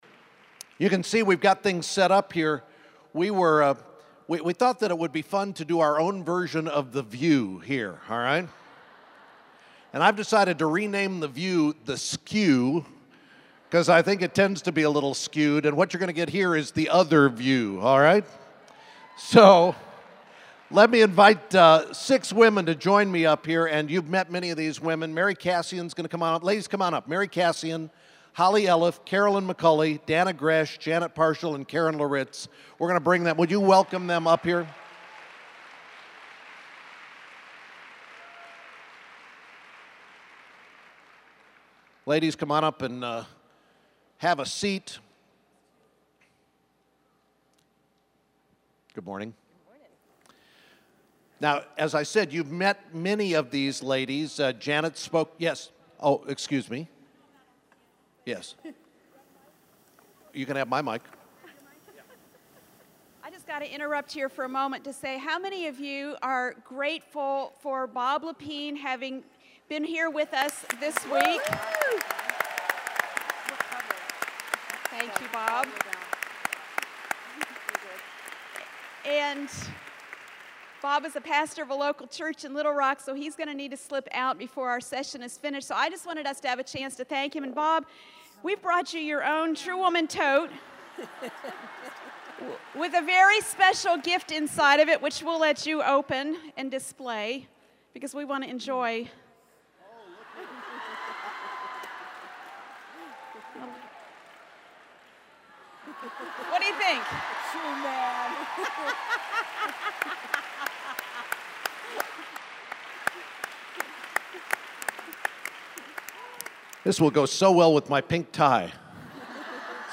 tw_indy_saturday_panel.mp3